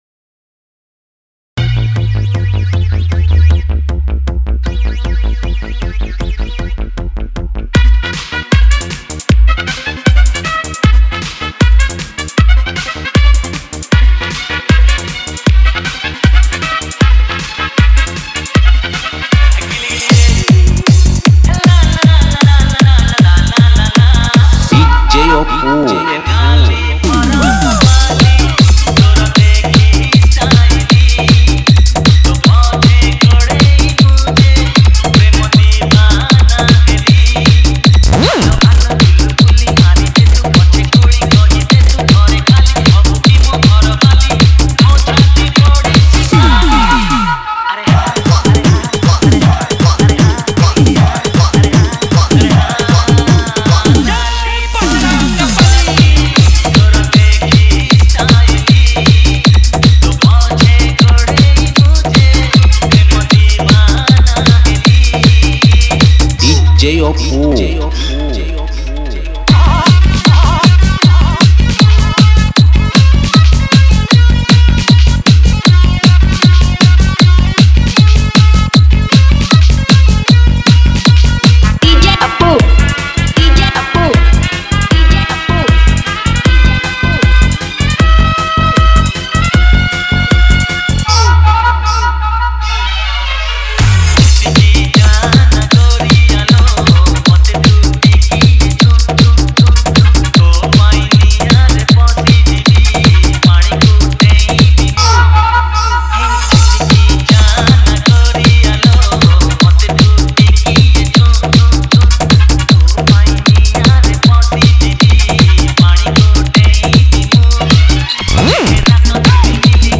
Category:  Odia Old Dj Song